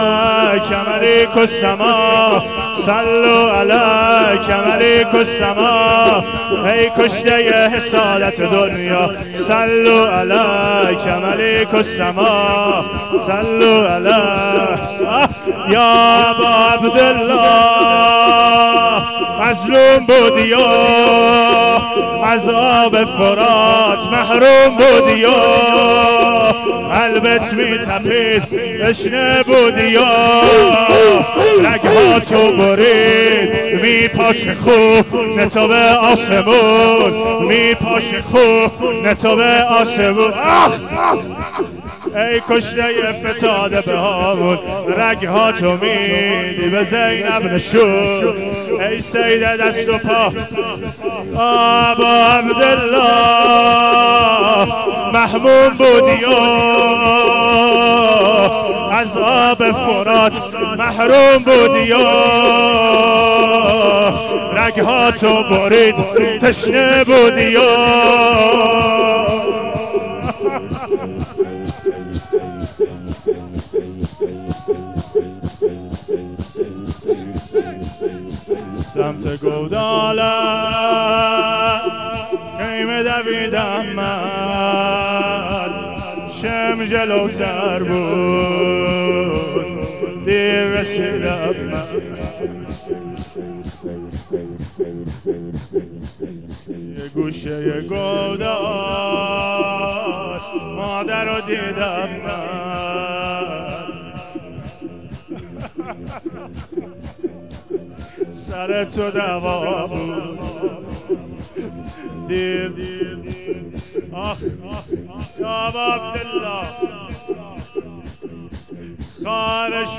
شورلطمه زنی